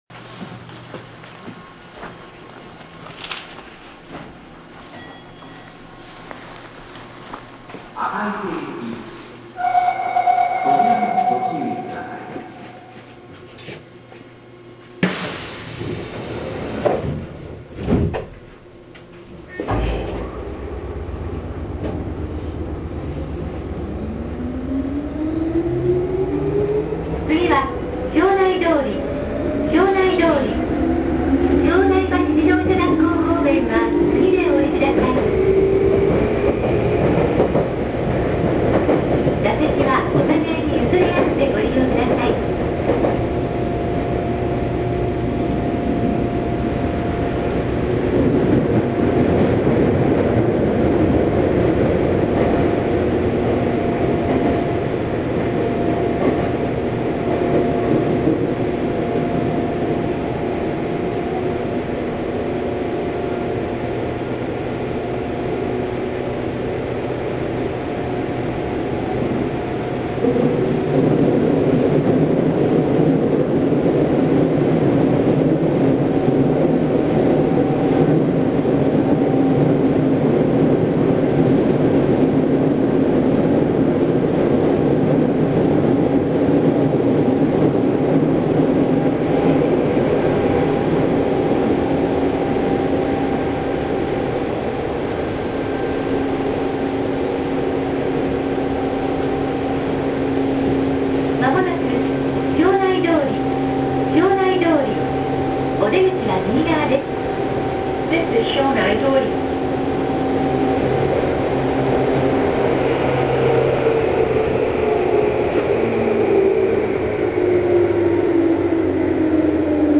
・100系（抵抗制御車）走行音
【名古屋市営鶴舞線】庄内緑地公園→庄内通（2分19秒：1.06MB）
抵抗制御の車両に関してはいかにもなそれっぽい音。
いかに車内が豪華であろうが、モーターの古さのごまかしまではききません。